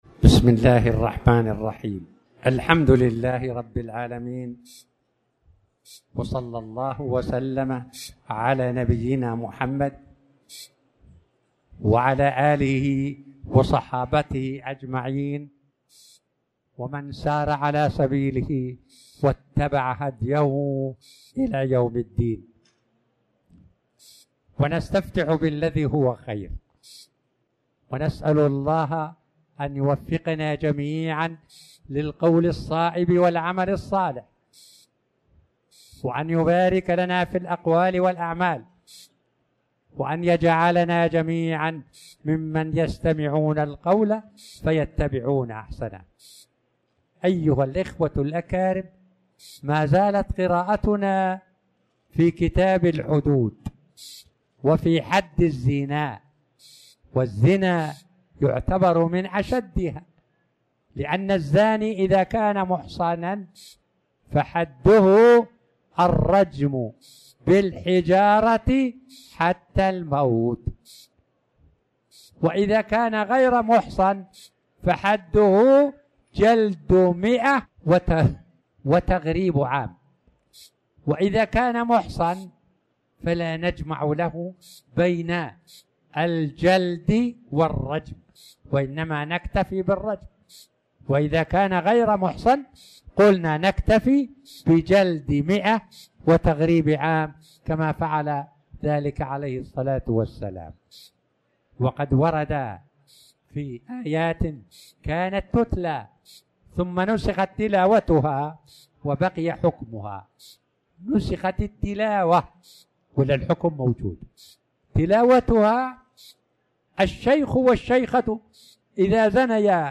تاريخ النشر ٢٥ ربيع الأول ١٤٤٠ هـ المكان: المسجد الحرام الشيخ